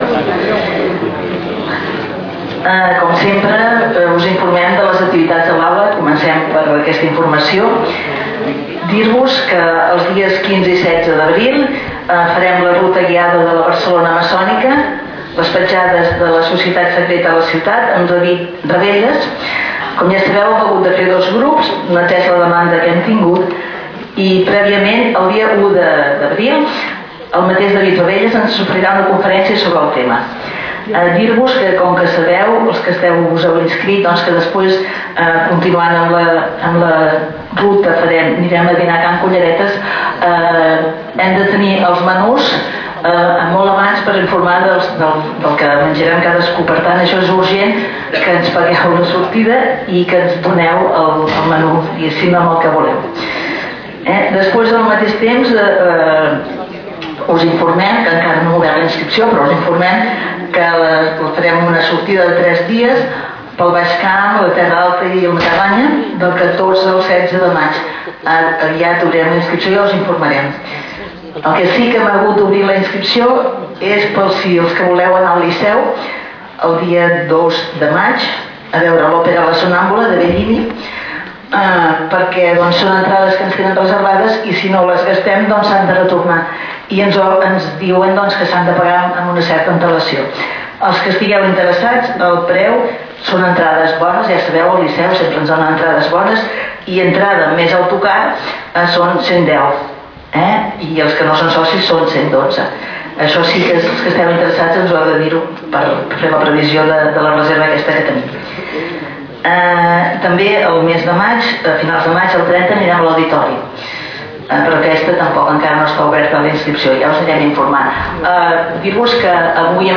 Lloc: Sala d'actes del Col.legi La Presentació
Categoria: Conferències